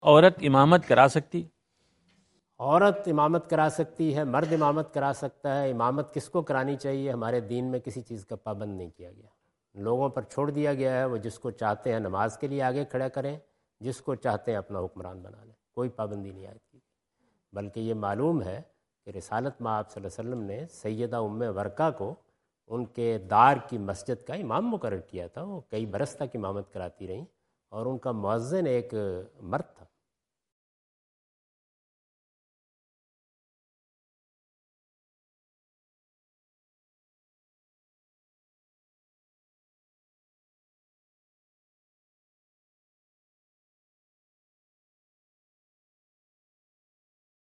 Category: English Subtitled / Questions_Answers /